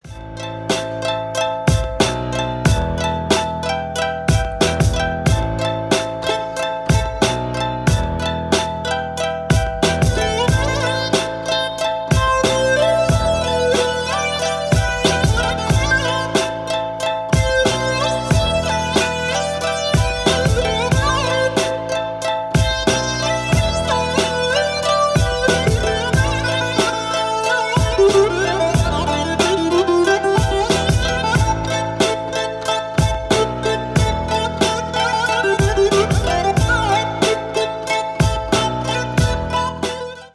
Ремикс
клубные # без слов